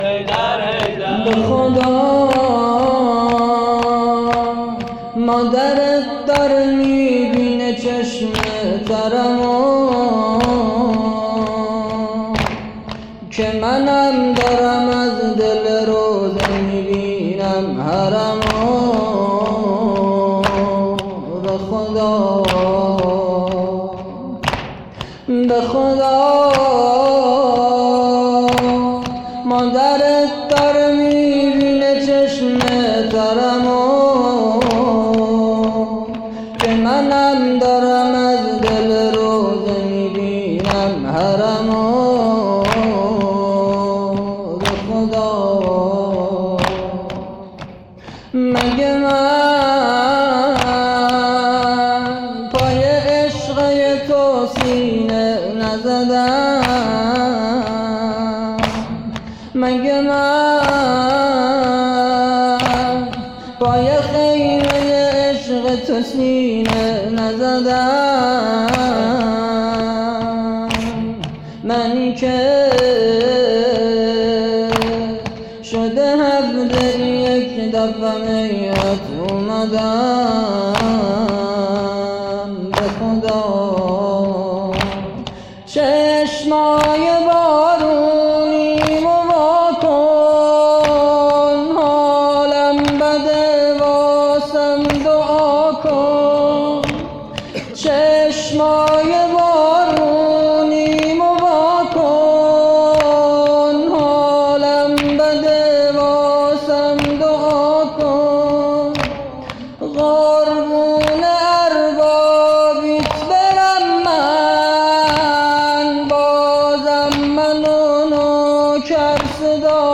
شور احساسی
فاطمیه 1401